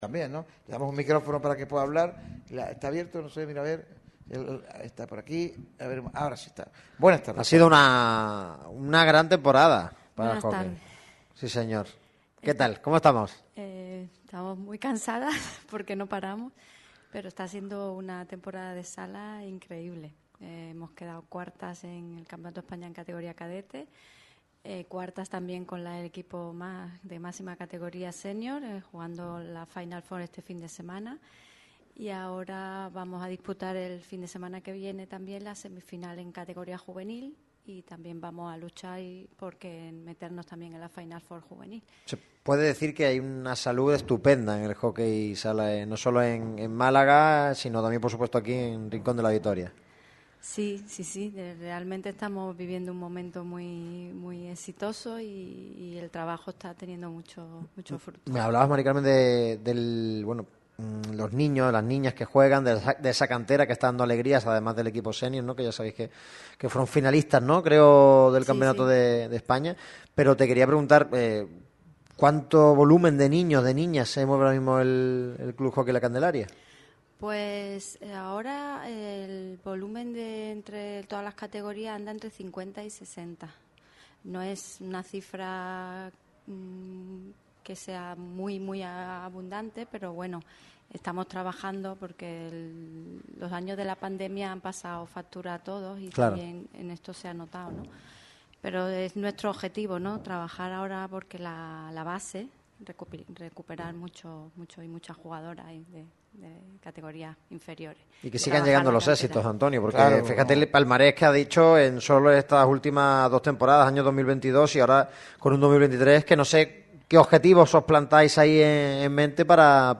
El evento ha tenido lugar en el Centro de Folclore de Torre de Benagalbón.